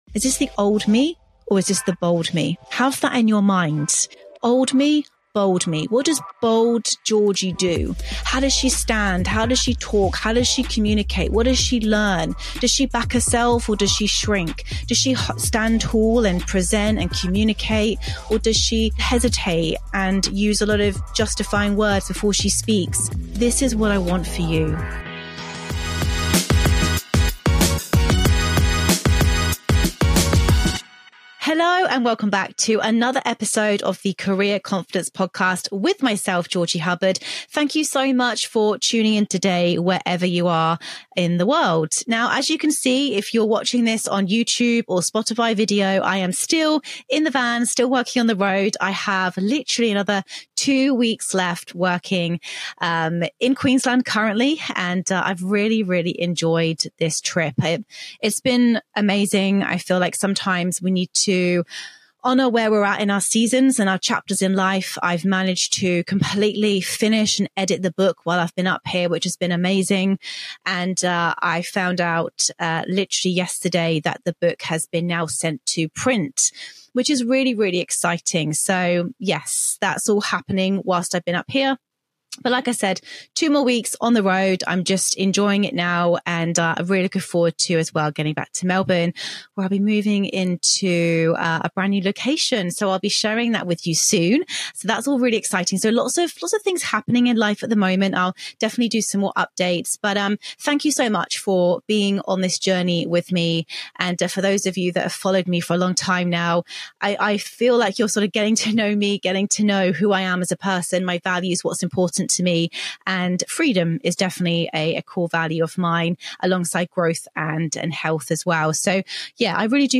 In this solo episode, I break down the 7 essential strategies you need to future-proof your career before AI disrupts your industry.